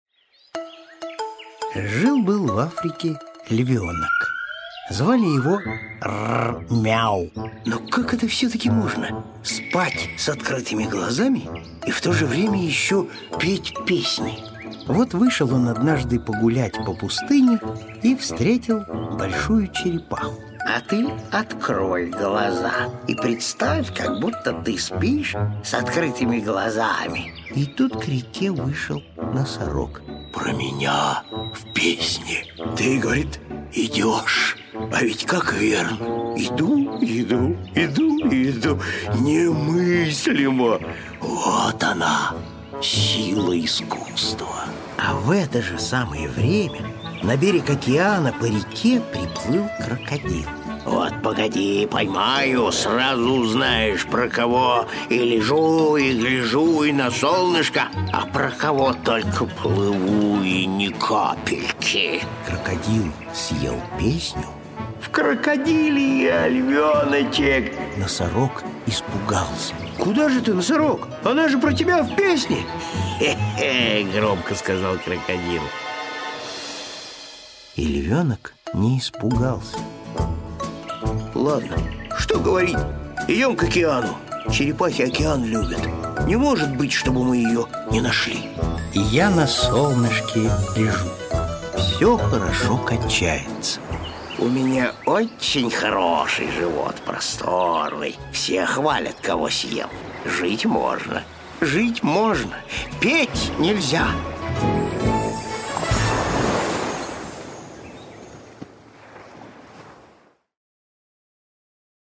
Аудиокнига Я на солнышке лежу | Библиотека аудиокниг